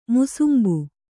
♪ musumbu